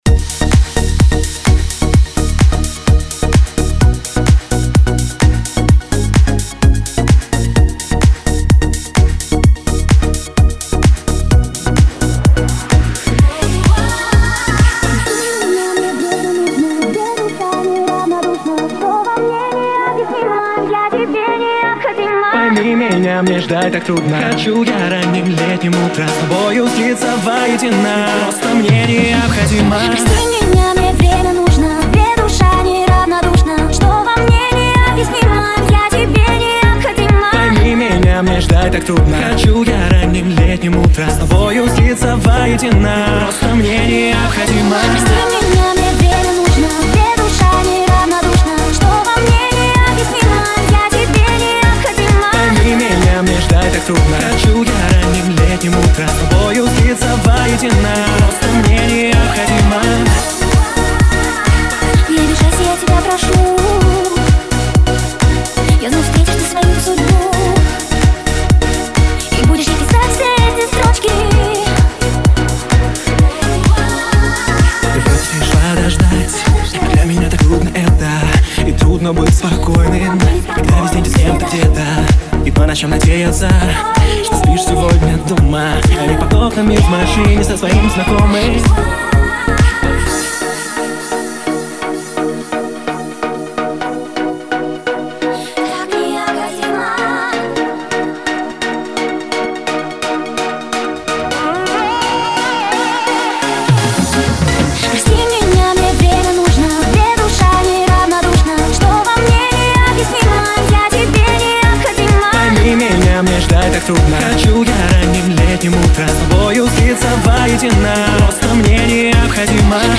лучшая pop-dance музыка
Жанр: Dance music